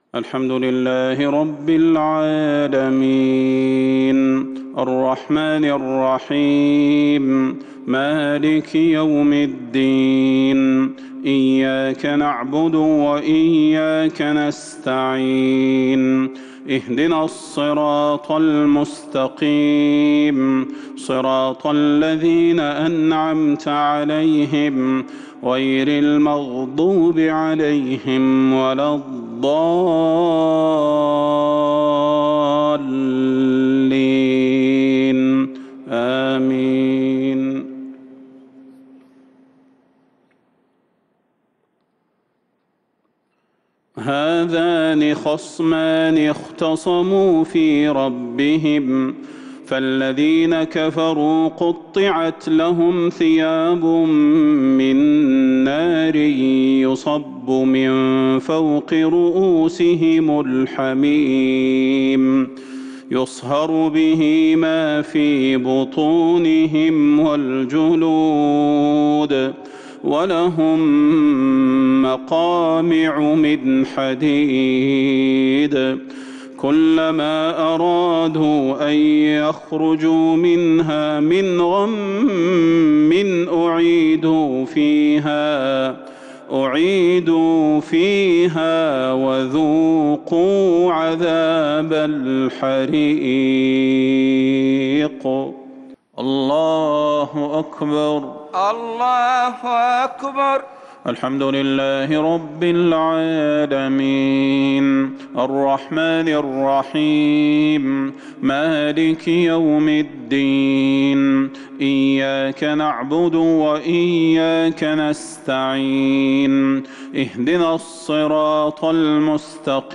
صلاة المغرب 2-2-1442 هـ من سورة الحج | Maghrib prayer from Surah Al-Hajj > 1442 🕌 > الفروض - تلاوات الحرمين